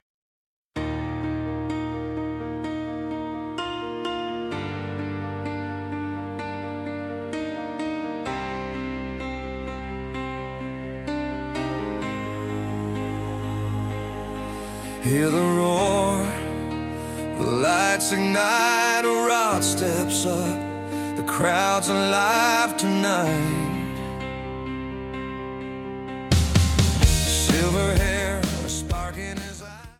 We Love Rod (Arena Anthem Ballad Version)